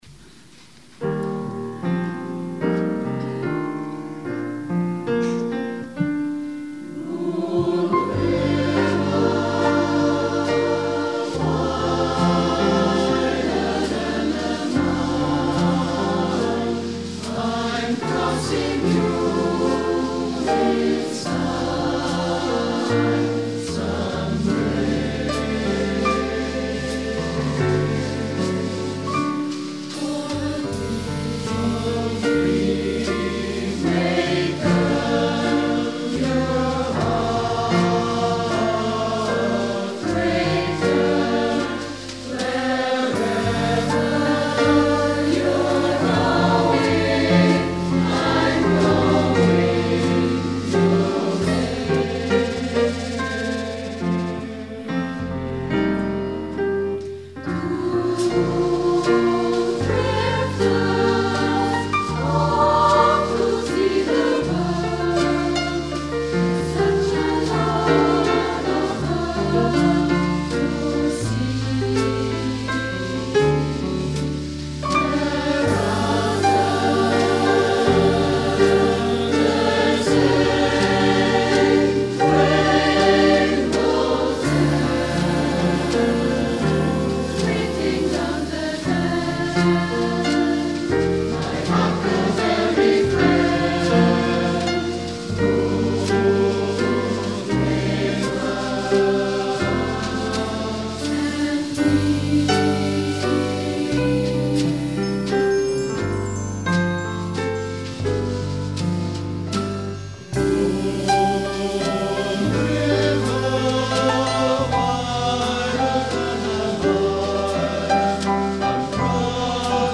Am 18. Oktober 2024 fand um 19:30 im Festsaal der Arbeiterkammer das große HERBSTKONZERT statt.